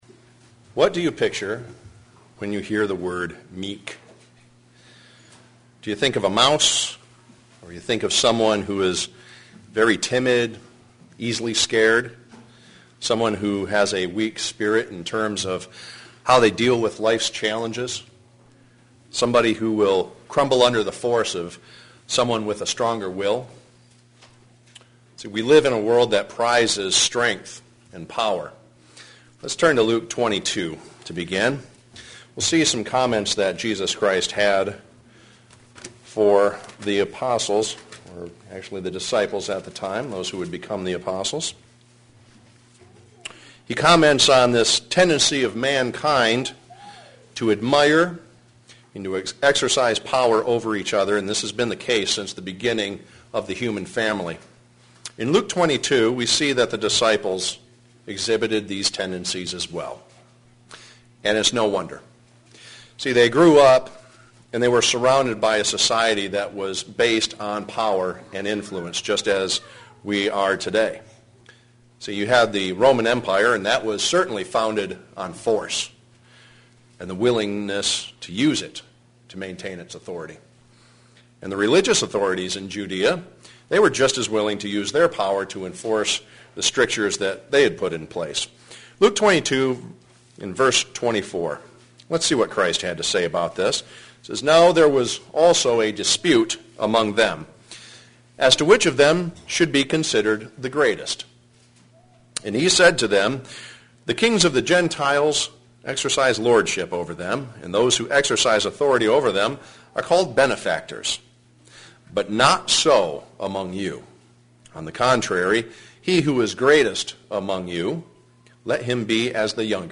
Today, we will look at the difference between meek and humble and how important they both are for us to embrace. sermon Studying the bible?